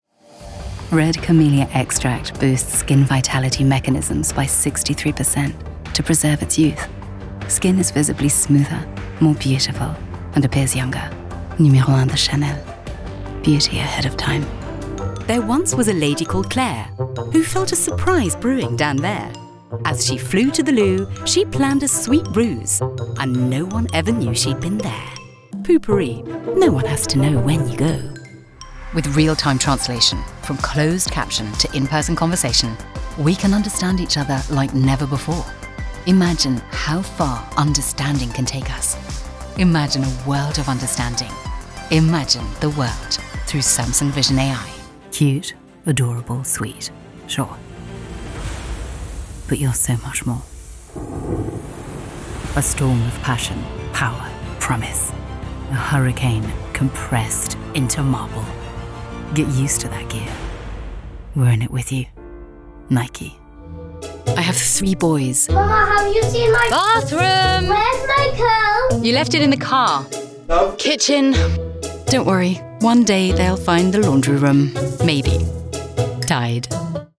Inglés (Británico)
Cálida, Llamativo, Versátil, Seguro, Natural
Comercial